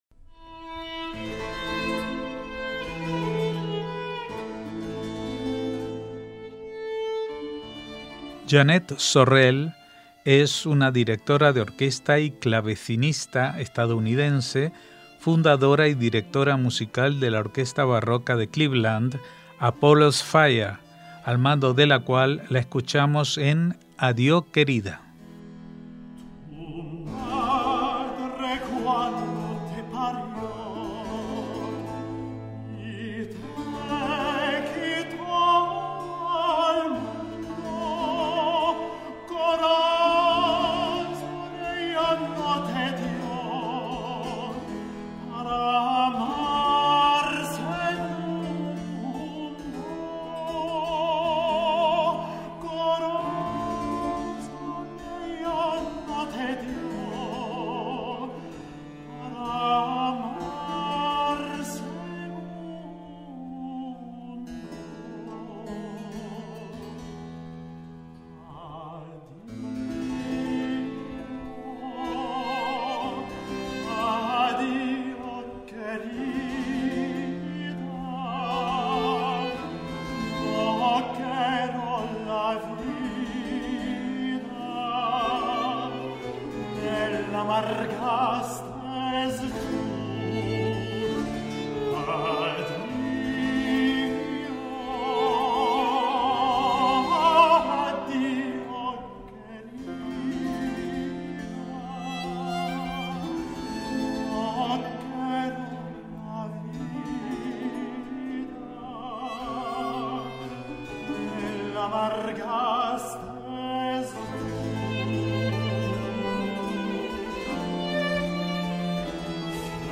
Apollo’s Fire: Jeanette Sorrell dirige música sefardí a toda orquesta